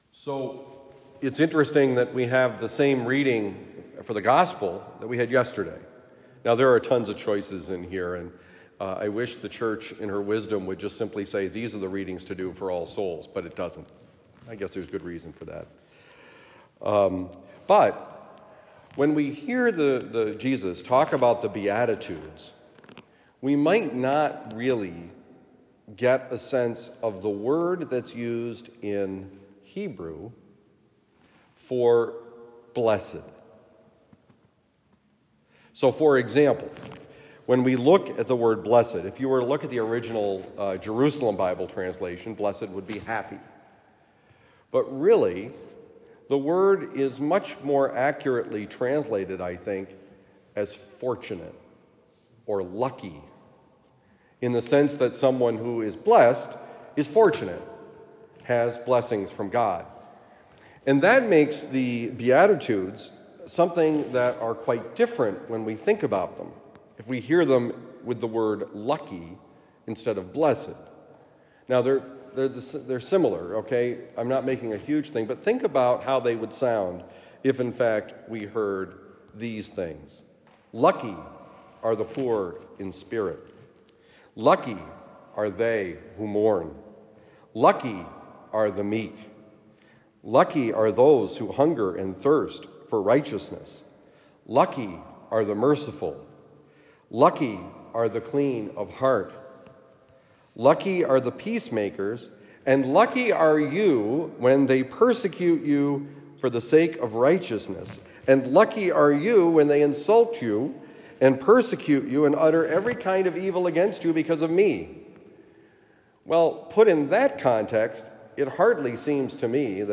Lucky? Homily for Thursday, November 2, 2023